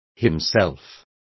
Complete with pronunciation of the translation of himself.